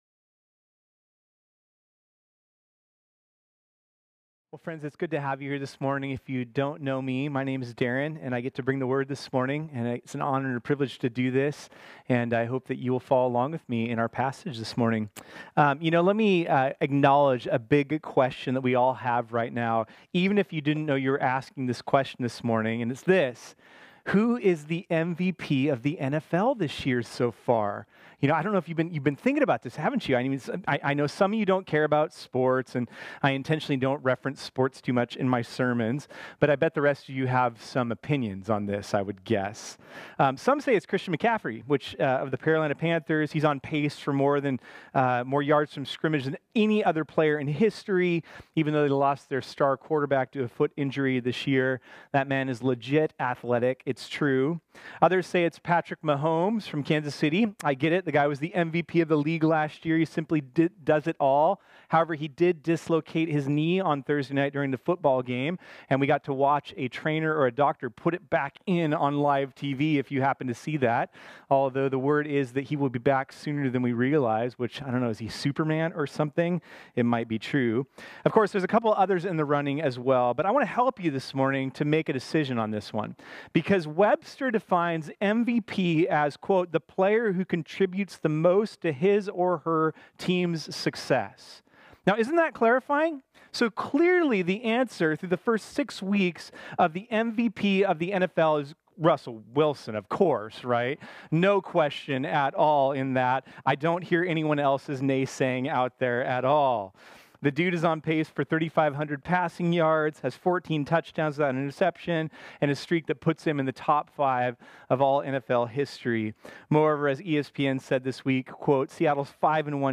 This sermon was originally preached on Sunday, October 20, 2019.